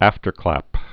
(ăftər-klăp)